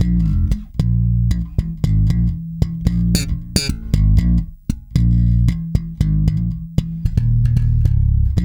-JP THUMB.A#.wav